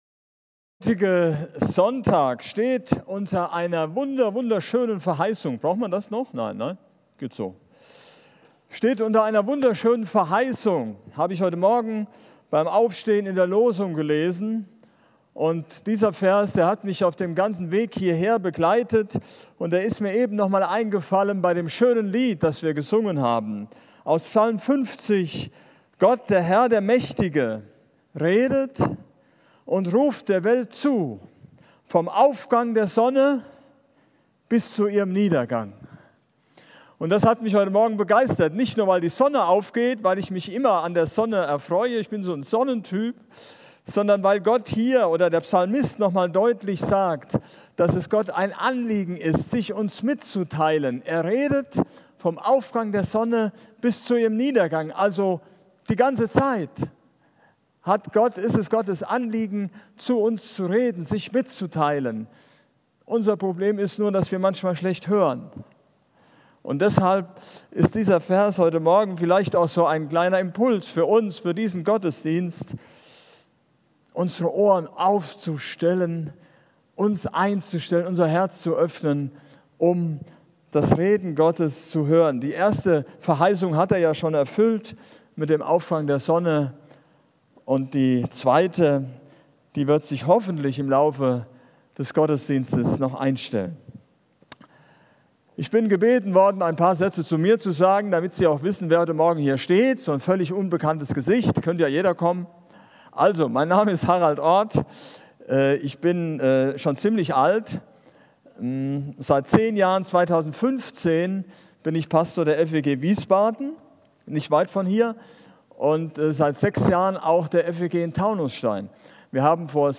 Predigt Start der Allianzgebetswoche